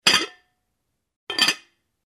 Звуки посуды
Крышку фарфоровой посуды снимают и надевают